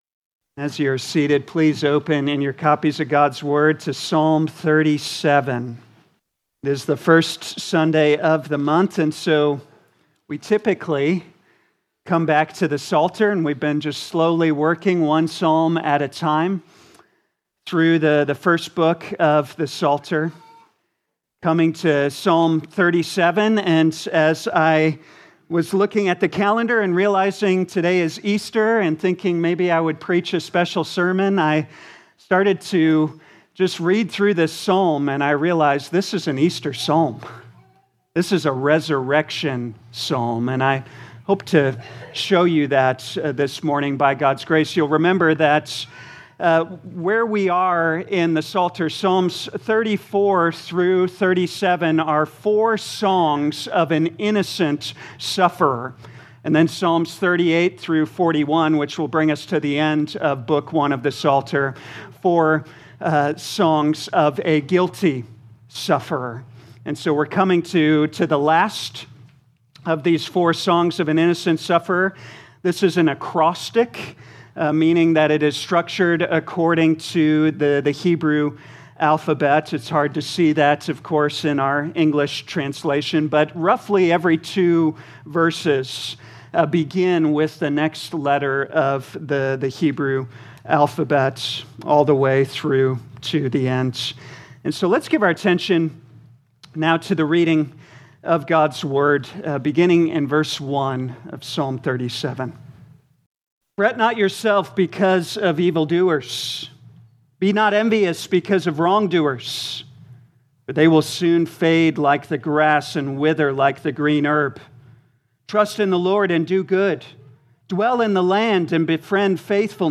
2026 Psalms Morning Service Download